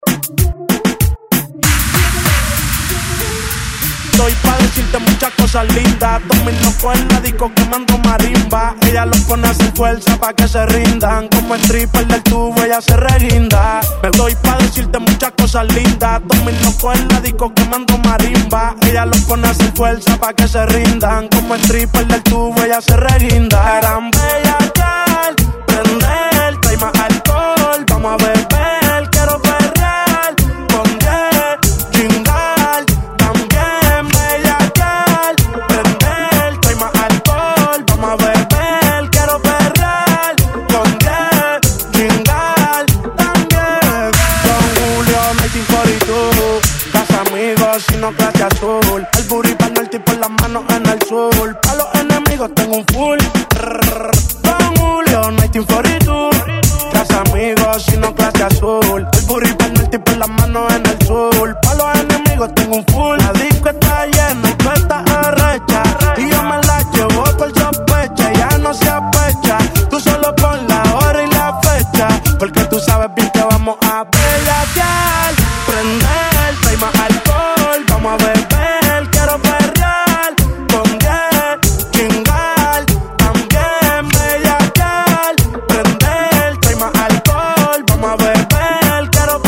Genre: TOP40
Clean BPM: 123 Time